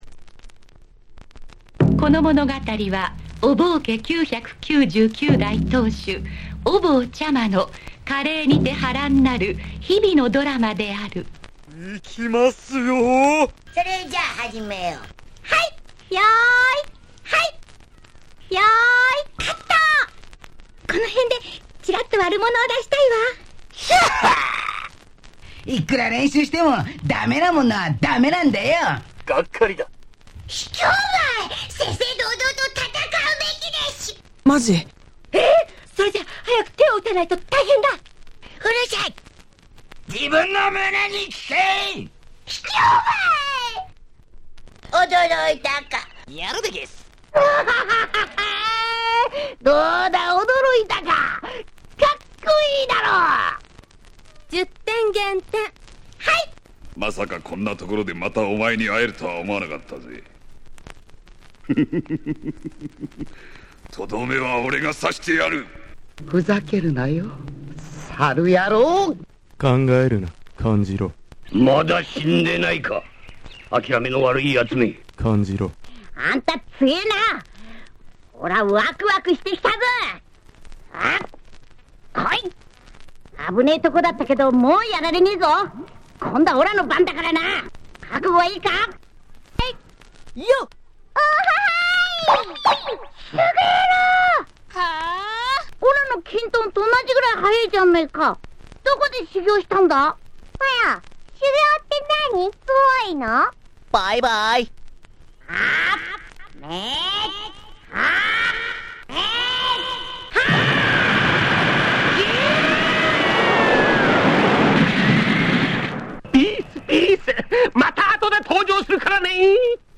07' Very Nice Battle Breaks !!
アニメ系声ネタをふんだんに盛り込んだ和製最強バトブレ！！
DJ向けに作られているため、ビートがカナリ効いています。
バトルブレイクス Battle Breaks コスリネタ スクラッチ